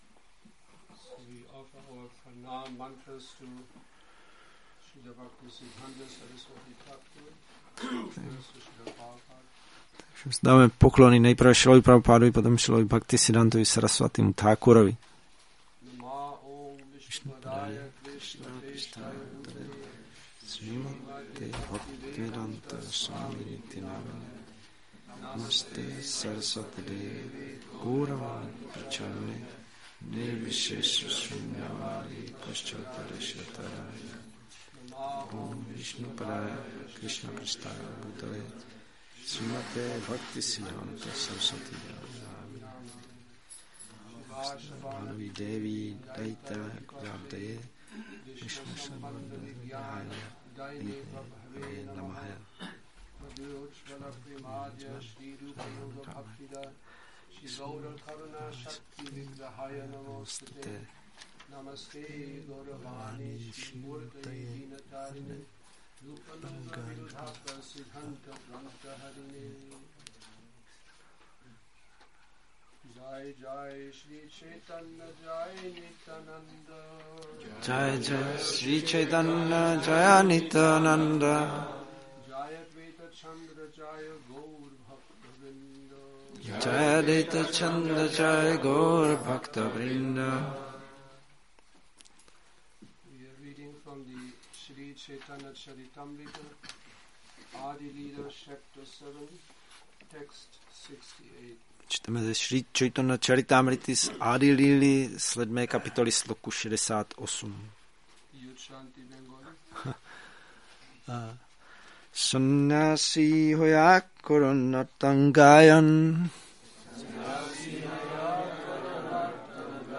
Přednáška CC-ADI-7.68, Šríla Bhaktisiddhánta Sarasvatí Thákura — Odchod